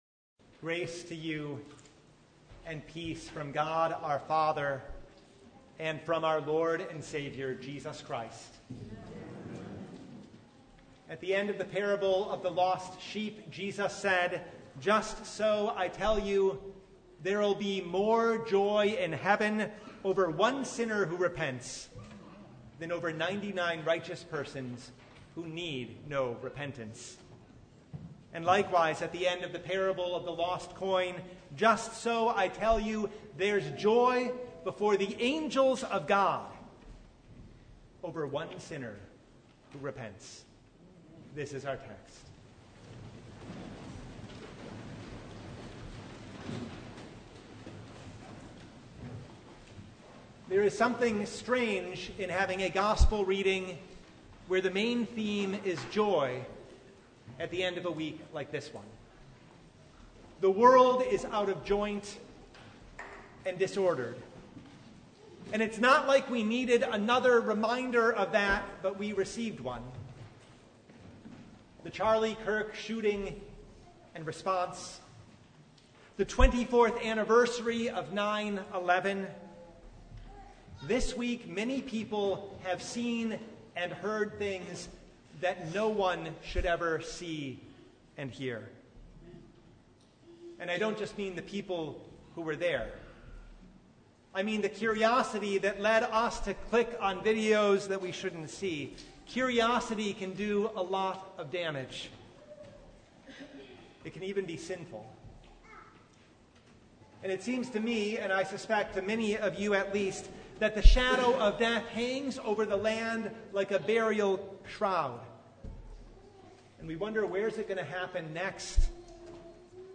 Luke 15:1-10 Service Type: Sunday What the world needs is a Shepherd.